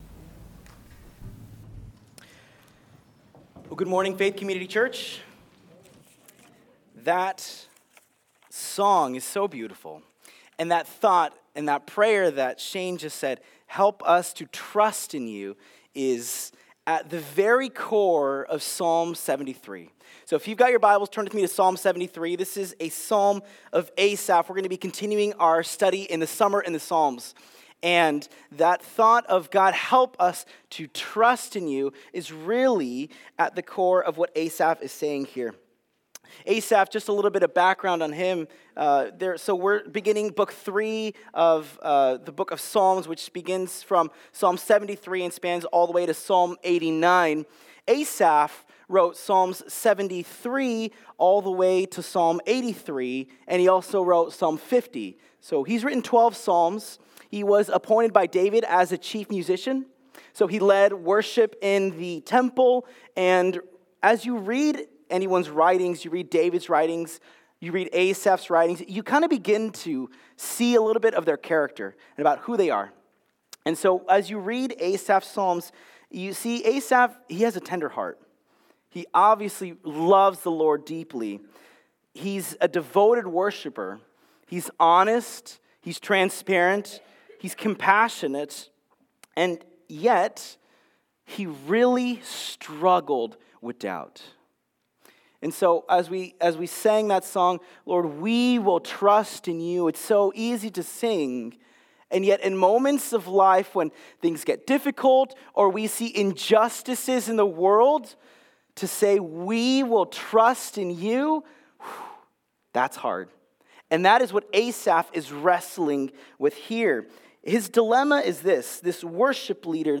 Passage: Psalm 73 Service Type: Sunday Service